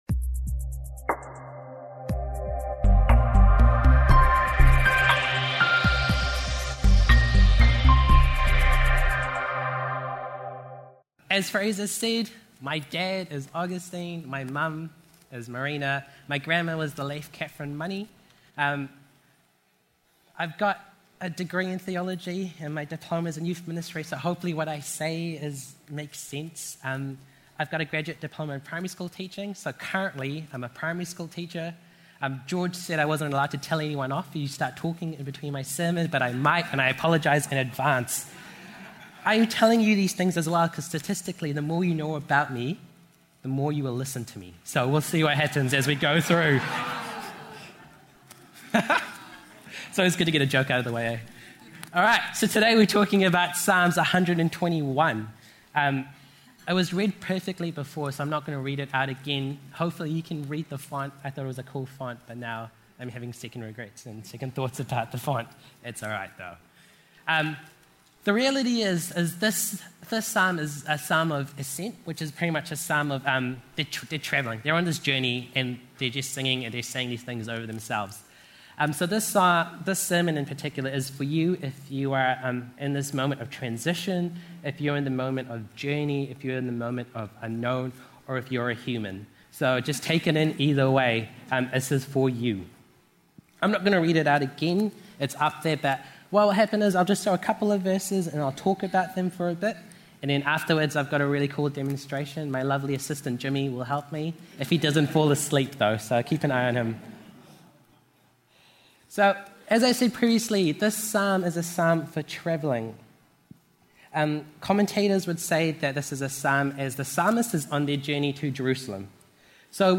Psalm 64 By St Margaret's Church 2019 Sermons